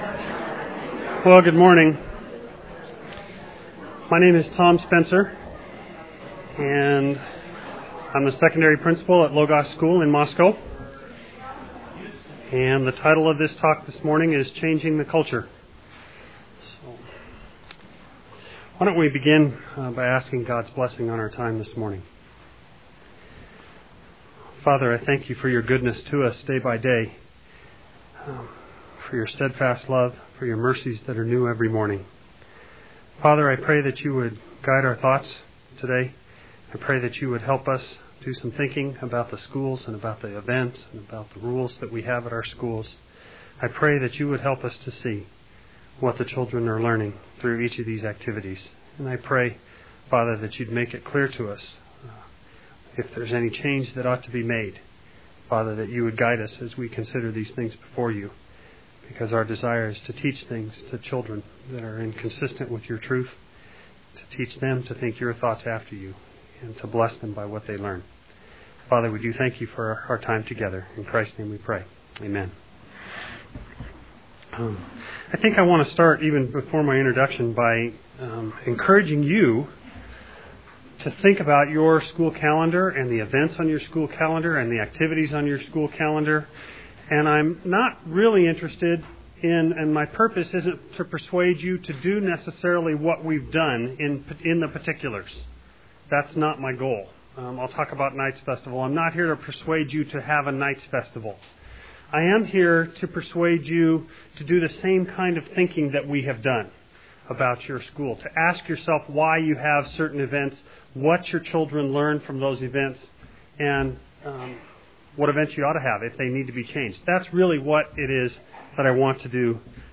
2002 Workshop Talk | 0:59:11 | All Grade Levels, Culture & Faith
The Association of Classical & Christian Schools presents Repairing the Ruins, the ACCS annual conference, copyright ACCS.